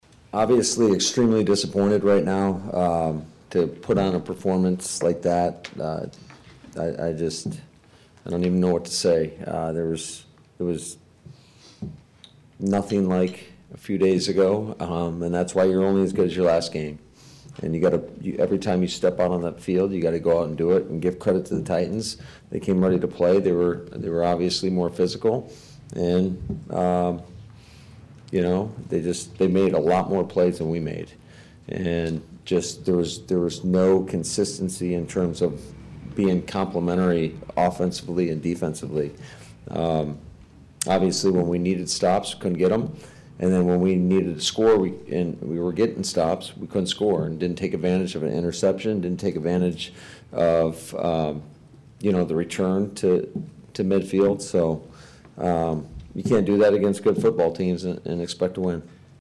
The performance left Head Coach Matt LaFleur ashen in his post-game press conference.